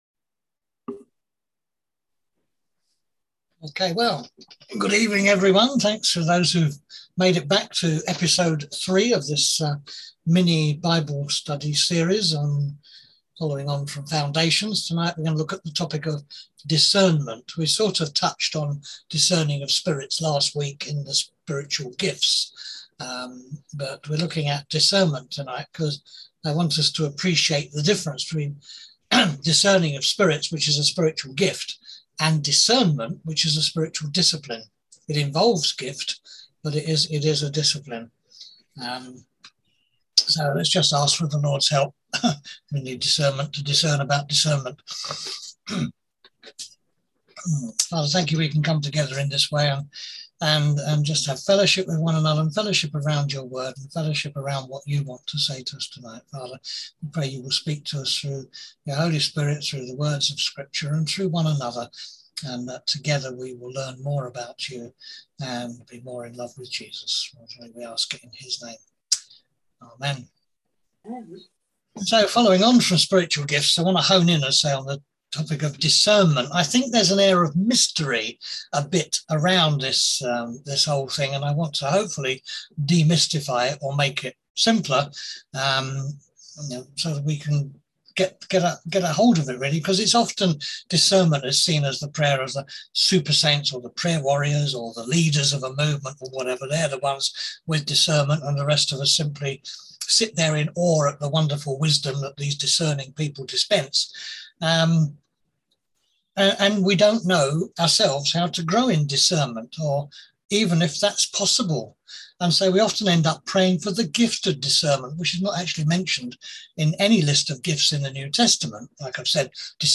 On August 25th at 7pm – 8:30pm on ZOOM